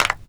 Index of /musicradar/essential-drumkit-samples/Dusty Break Kit
Dusty Clap.wav